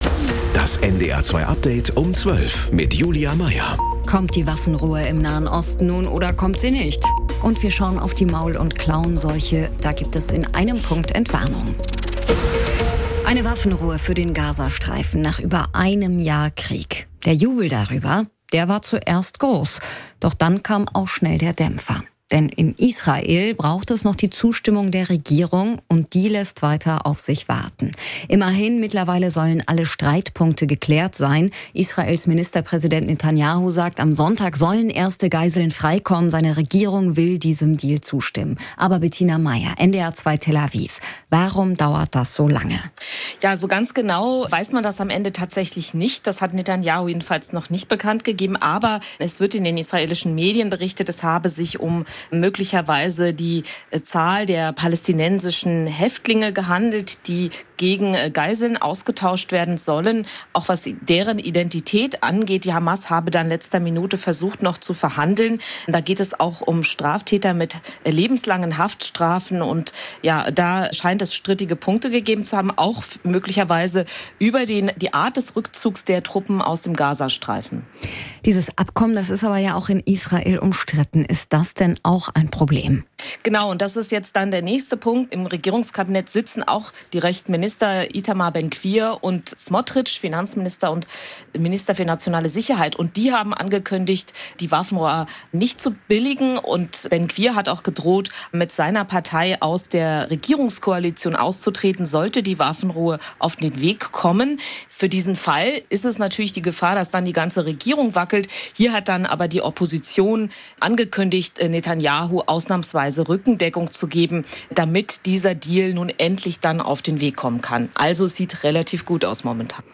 Mit unseren Korrespondent*innen und Reporter*innen, im Norden, in Deutschland und in der Welt.